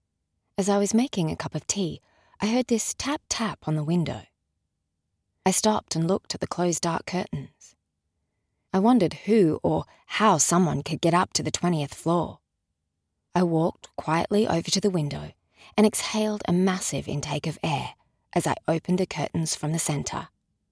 English (Australia)
Natural, Playful, Reliable, Friendly, Warm
Audio guide